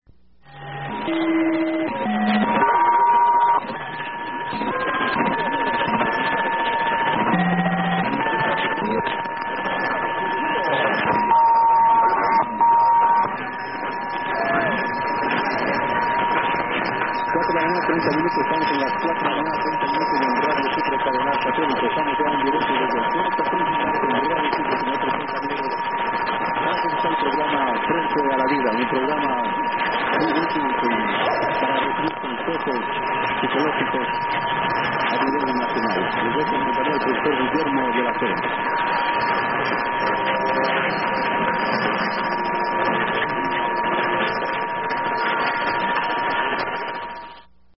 Clear Radio Sucre ID - but nothing listed